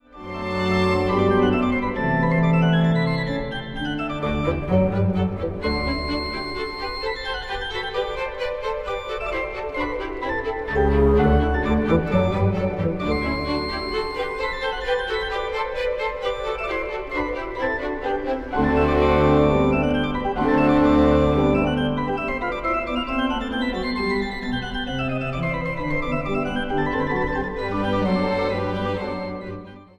Instrumentaal | Orkest
+ mooie opname